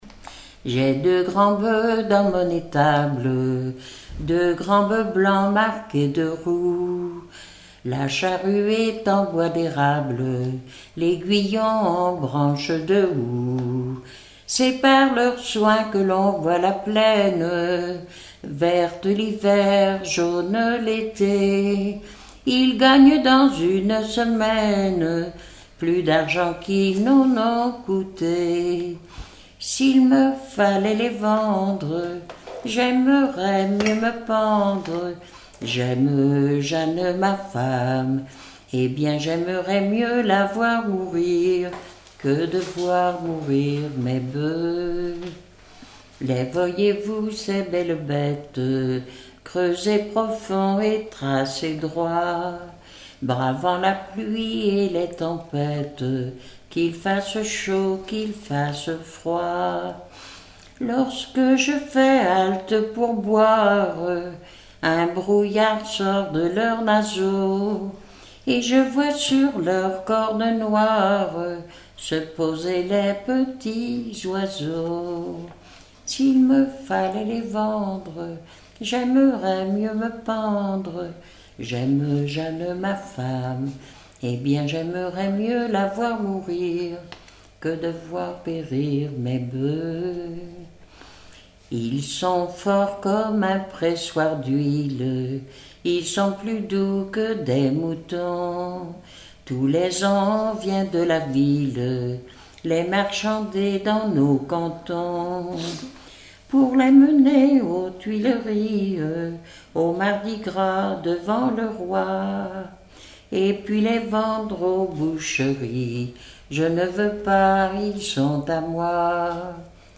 Localisation Saint-Vincent-Sterlange
Genre strophique
Catégorie Pièce musicale inédite